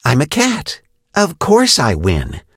kit_lead_vo_02.ogg